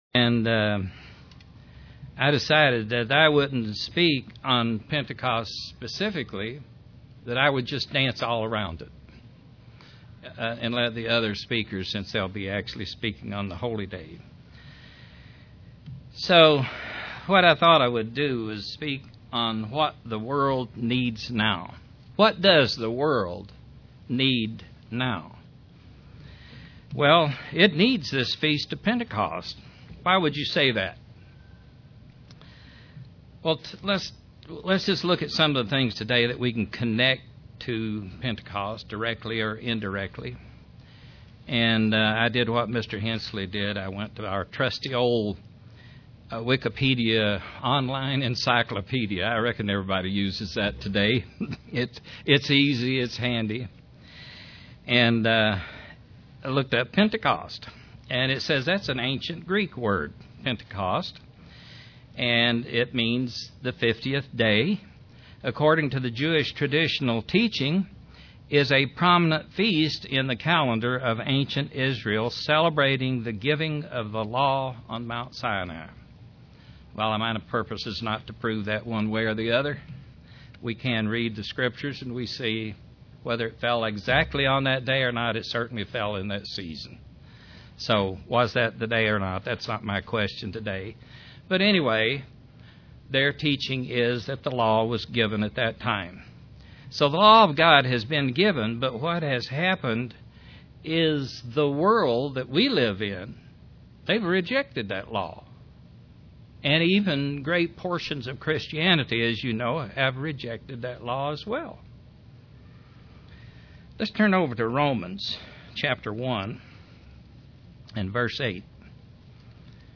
Print The world needs the return of Christ and the Kingdom of God UCG Sermon Studying the bible?